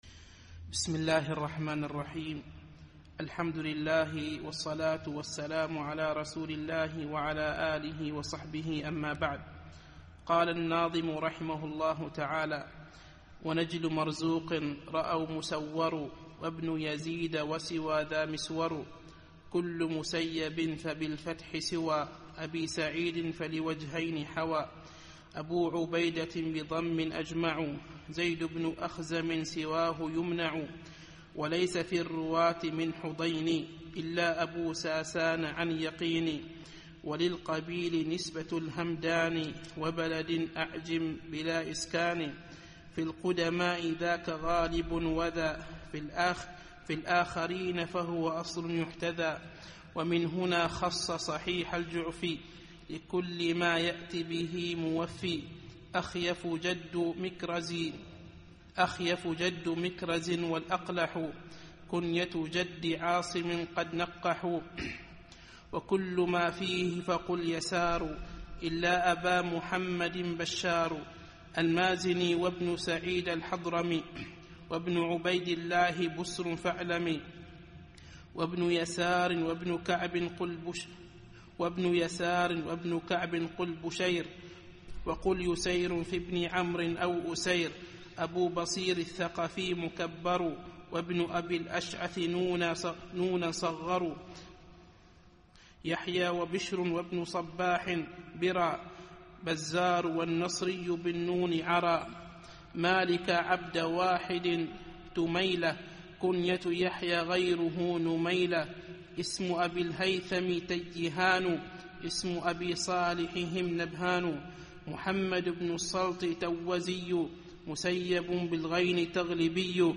الدرس السابع والثلاثون